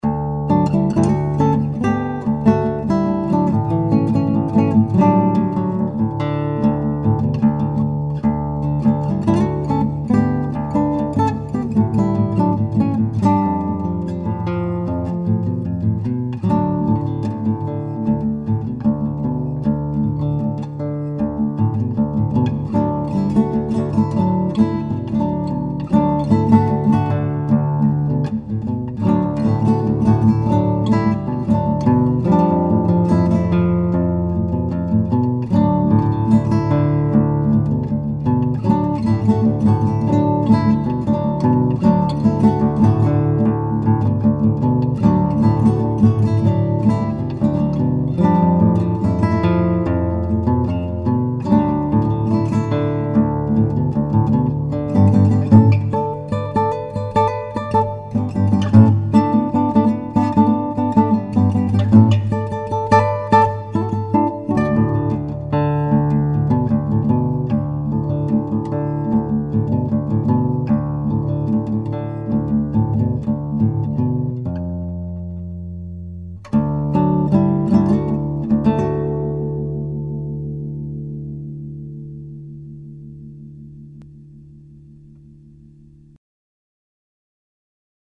Solo Guitar Demos – Click Here
SOLOS